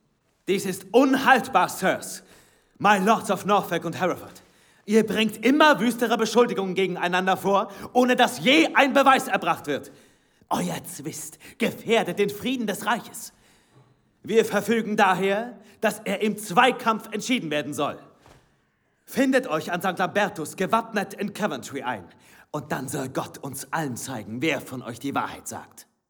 Hörspiel